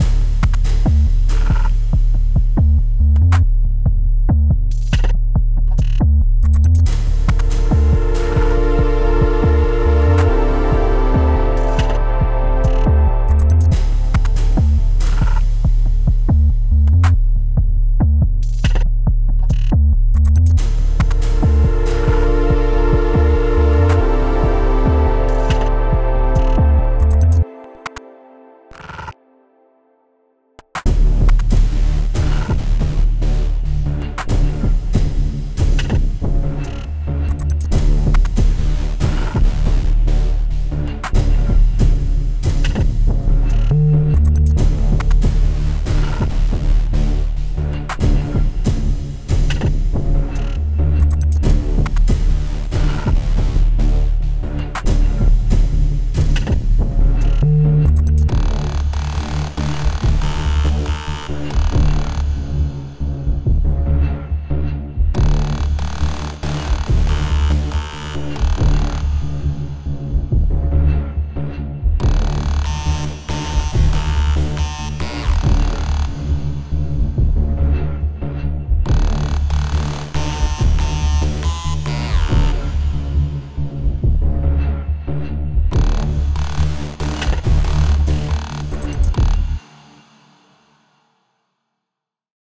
Added Ambient music pack. 2024-04-14 17:36:33 -04:00 25 MiB Raw Permalink History
Ambient Working Intensity 2.wav